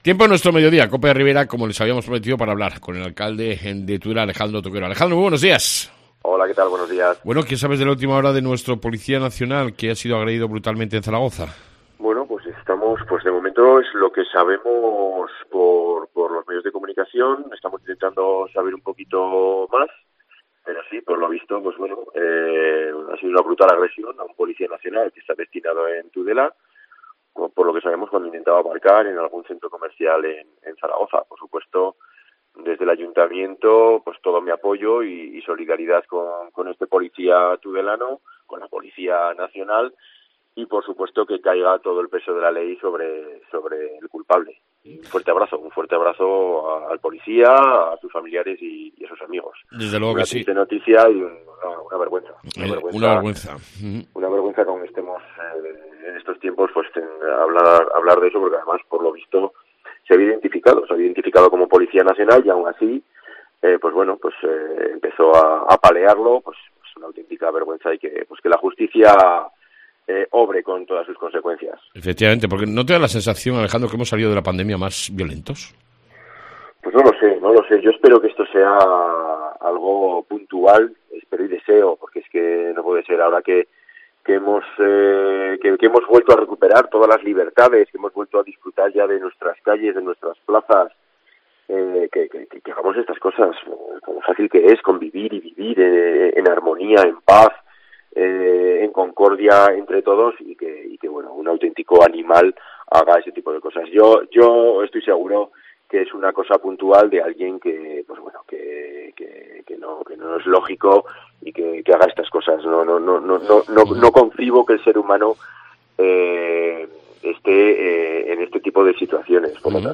AUDIO: hablaMos con el Alcalde de Tudela, Alejandro Toquero, sobre diversos asuntos de interés